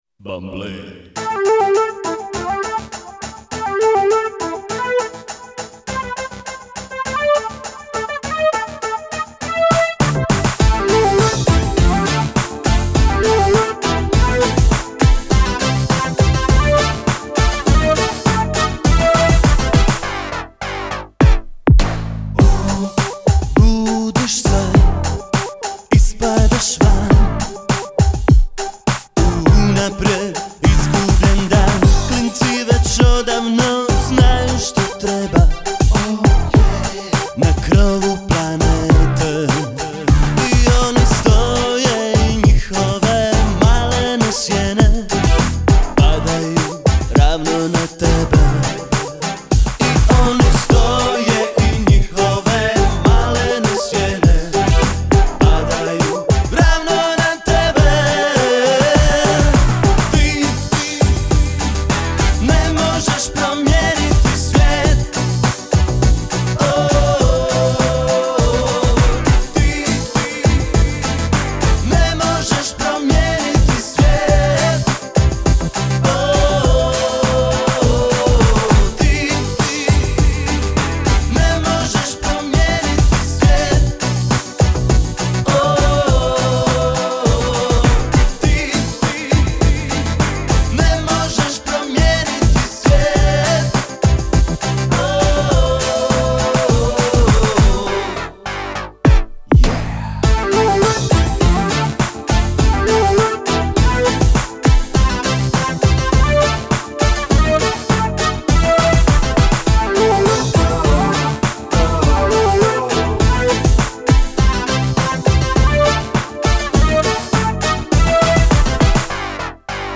Pop - Dance/Reggae-Ska